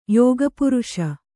♪ yōga puruṣa